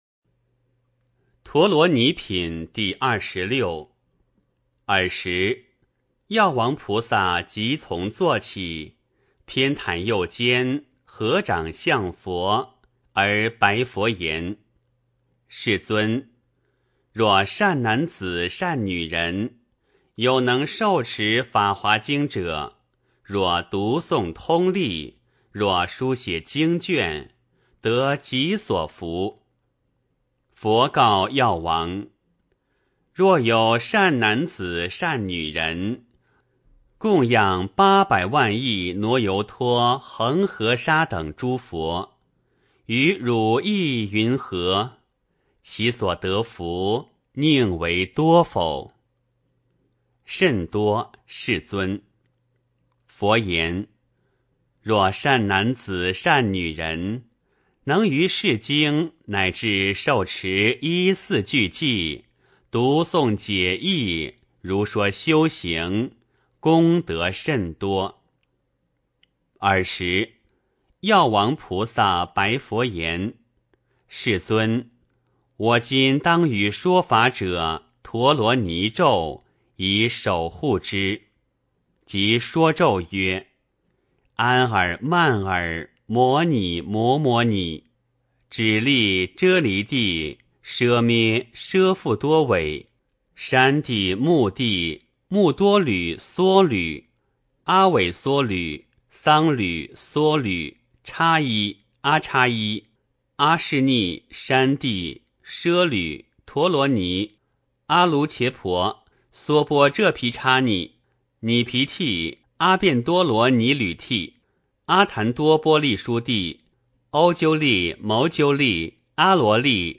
法华经-陀罗尼品第二十六 - 诵经 - 云佛论坛